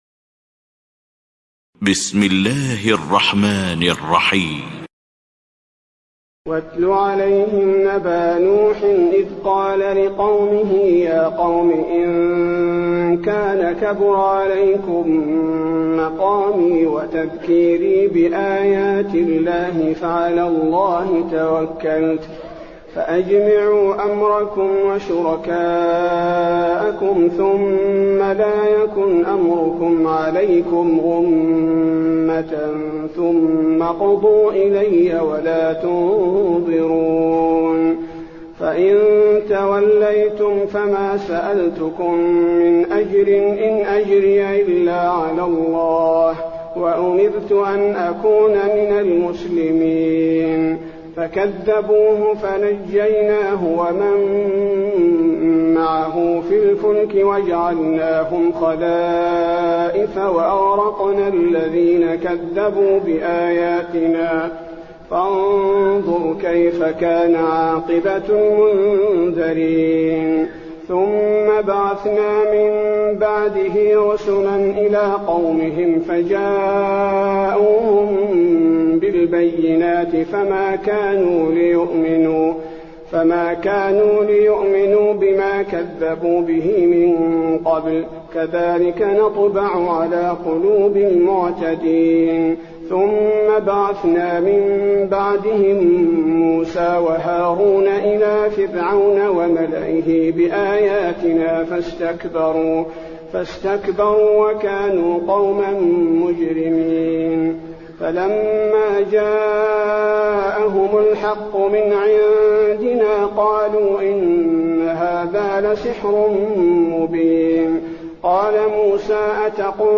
تهجد ليلة 29 رمضان 1423هـ من سورة يونس (71-109) Tahajjud 29 st night Ramadan 1423H from Surah Yunus > تراويح الحرم النبوي عام 1423 🕌 > التراويح - تلاوات الحرمين